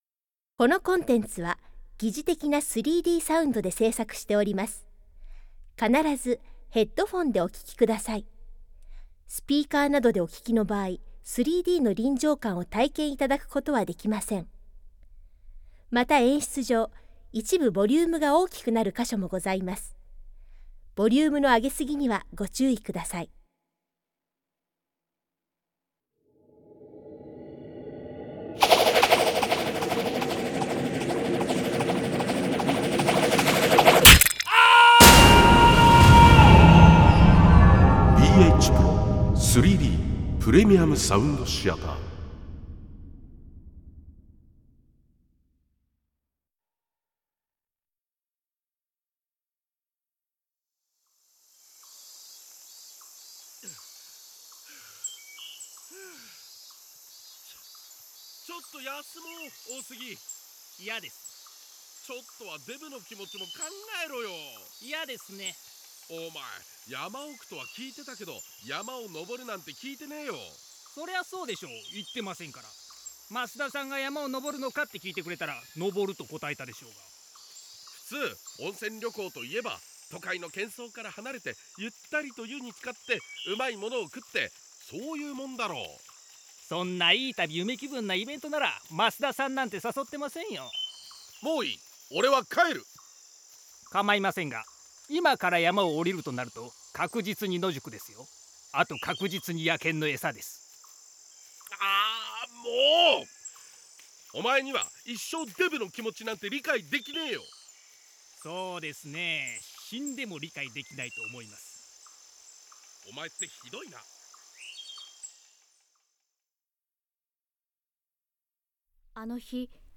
3Dサウンドだから体験出来る臨場感！！オーディオドラマ仕立てのミステリー集！！
※このコンテンツは3Dサウンドで制作されております。ステレオ式ヘッドフォン（イヤホン）でお聴きください。